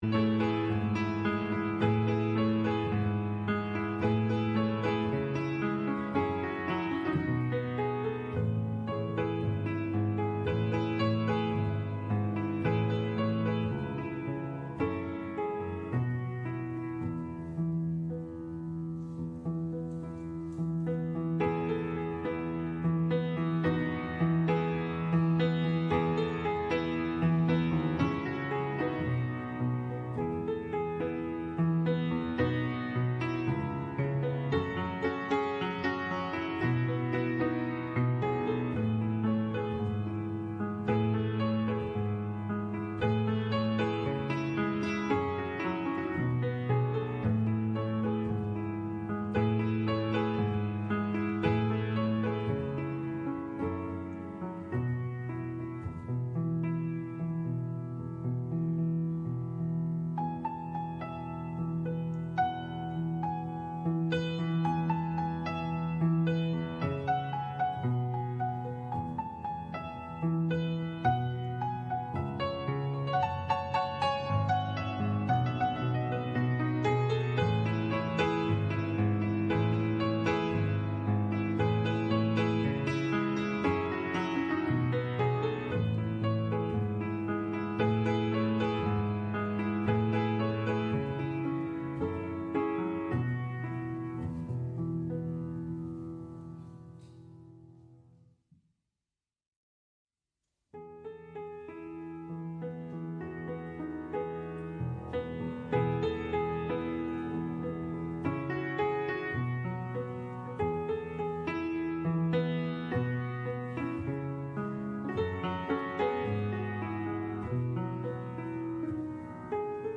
Gottesdienst am 7. Januar 2024 aus der Christuskirche Altona